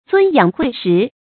遵養晦時 注音： ㄗㄨㄣ ㄧㄤˇ ㄏㄨㄟˋ ㄕㄧˊ 讀音讀法： 意思解釋： 同「遵養時晦」。